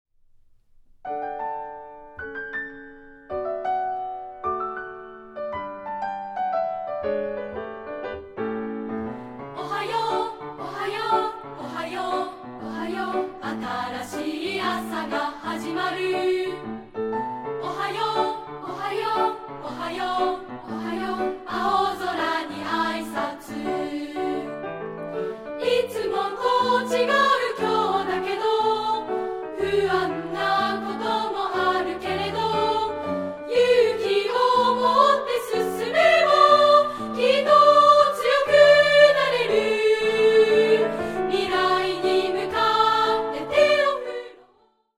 範唱＋カラピアノCD付き
部分2部合唱／伴奏：ピアノ